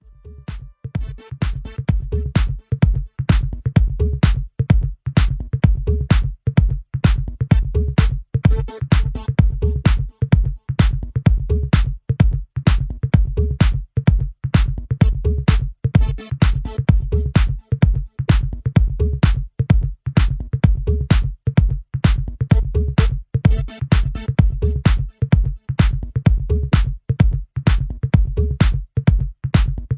High Energy Dance Beat